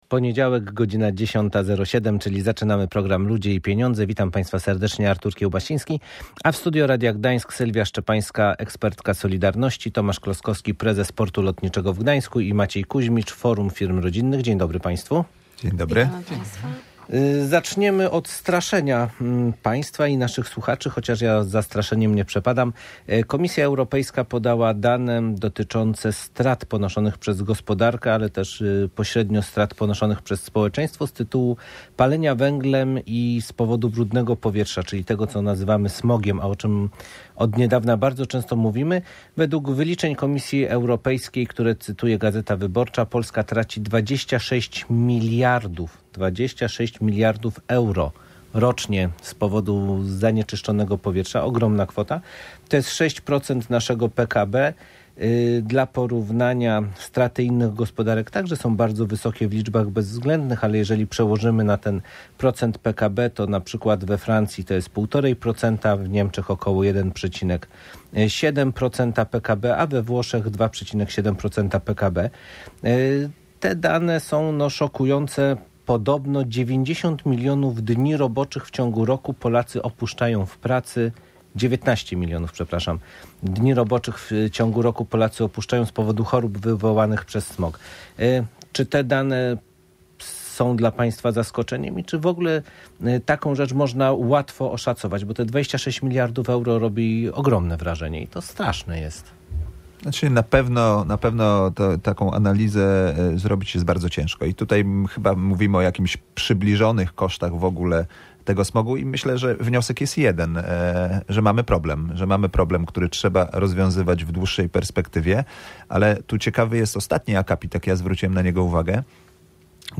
- Codziennie docierają do nas nowe zgłoszenia o nieprawidłowościach i naruszeniach płacy minimalnej - mówiła w audycji Ludzie i Pieniądze